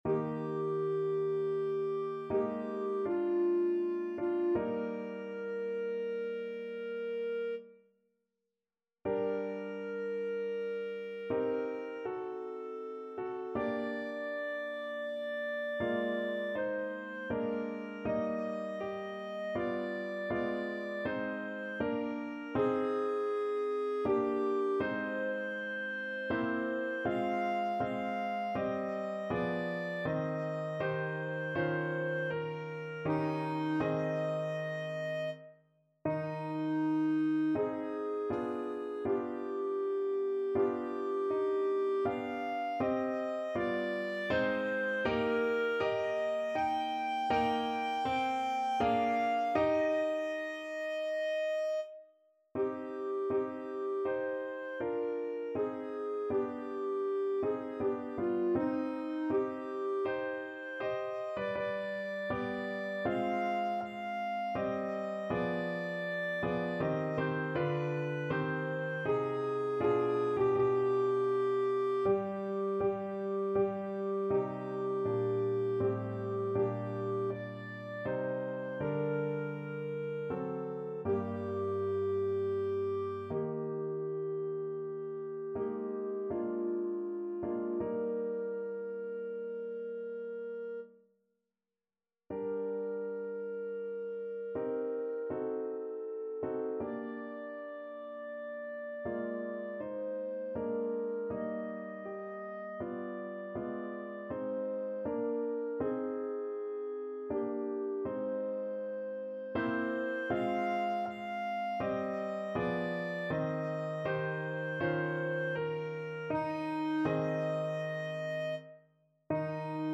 Clarinet
3/4 (View more 3/4 Music)
Eb major (Sounding Pitch) F major (Clarinet in Bb) (View more Eb major Music for Clarinet )
~ = 80 Andante ma non lento
Classical (View more Classical Clarinet Music)